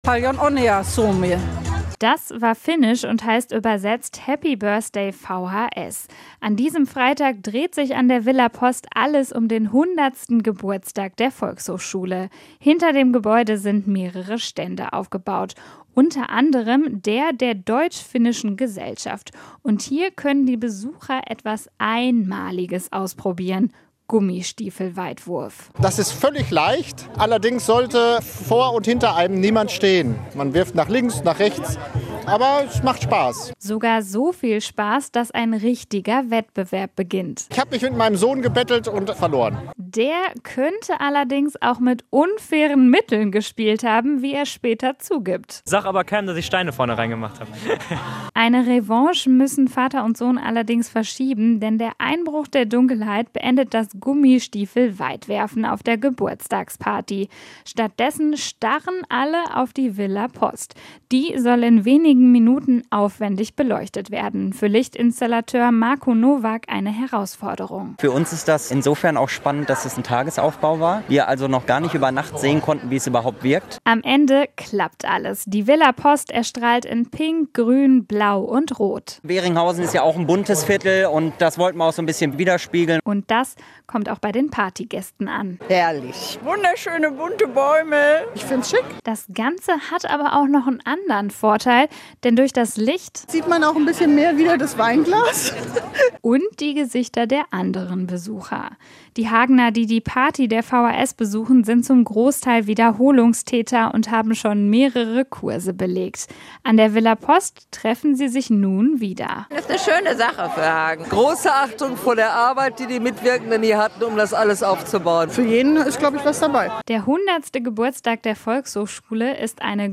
Die Volkshochschulen gibt es nämlich seit 100 Jahren. Auch in Hagen war Partystimmung angesagt. Rund um die Villa Post wurde bis Mitternacht gefeiert.